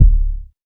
kick 25.wav